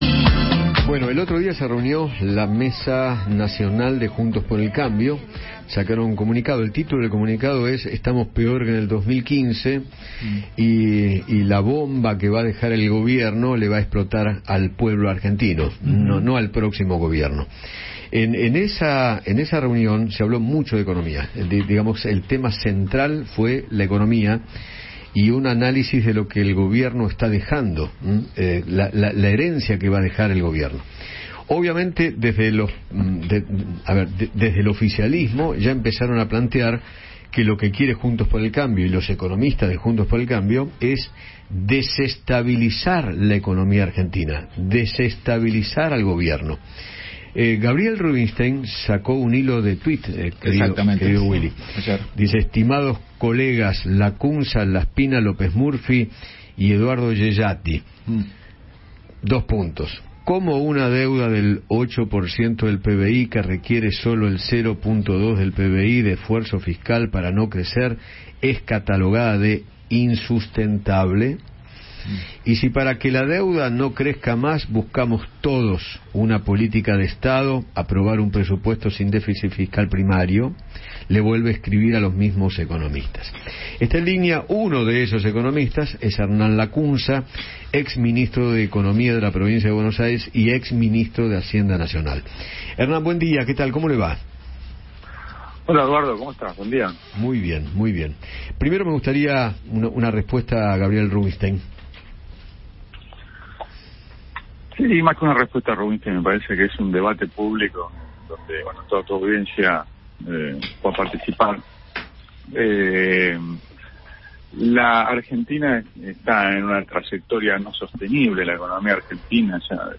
Hernán Lacunza, ex ministro de Economía durante el gobierno de Mauricio Macri, conversó con Eduardo Feinmann sobre los dichos de Gabriel Rubinstein acerca de la deuda pública y analizó la coyuntura política y económica del país.